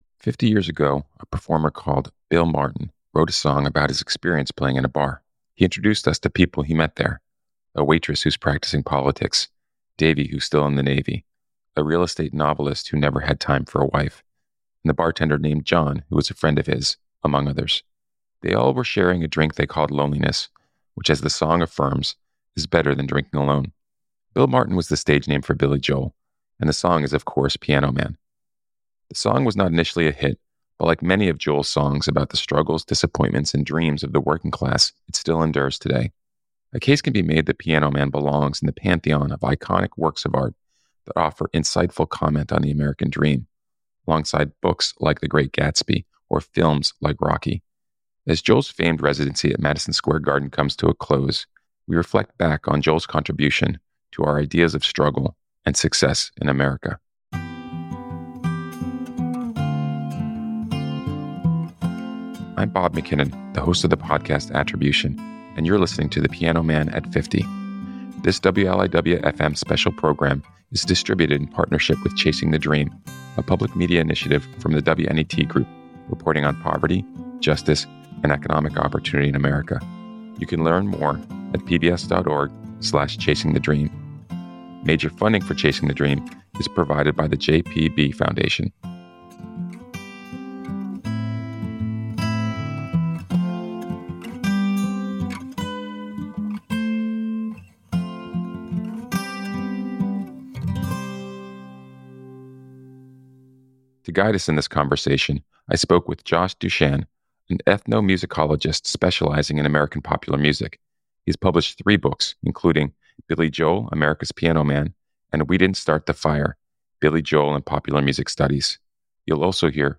You’ll also hear from the Piano Man himself, Billy Joel.